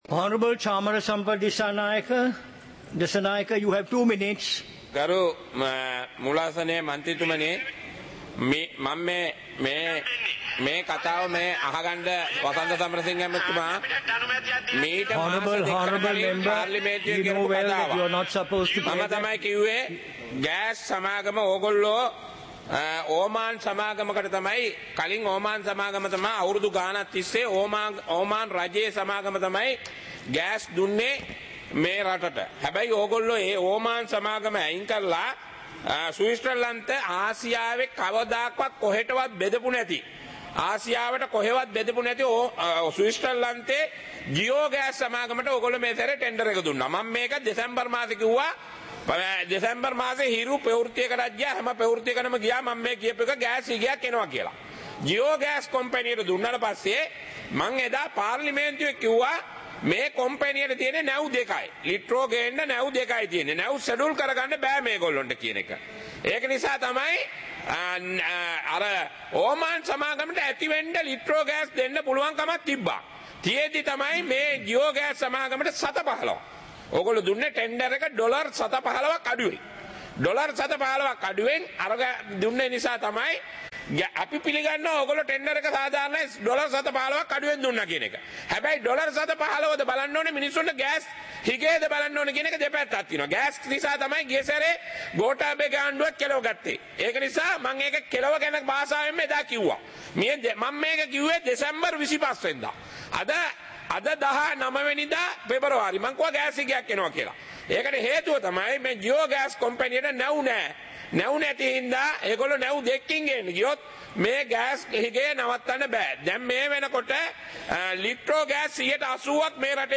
சபை நடவடிக்கைமுறை (2026-02-19)